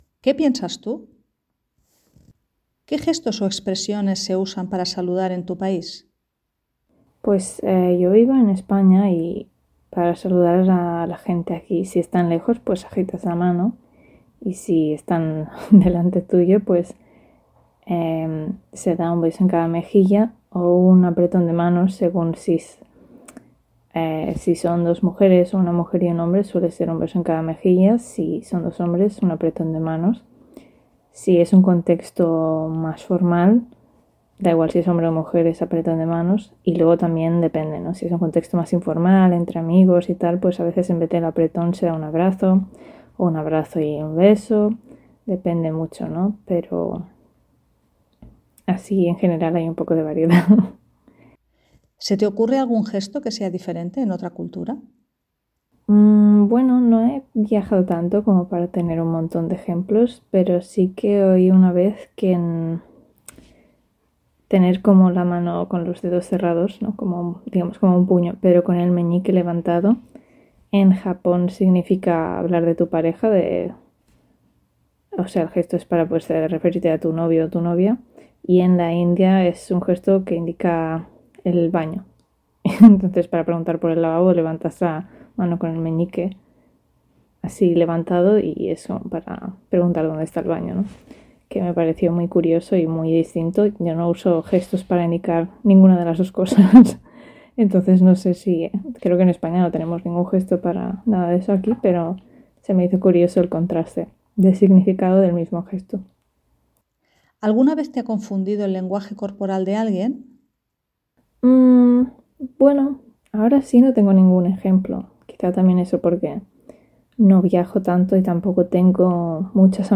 Ahora escucha a un nativo que contesta las preguntas.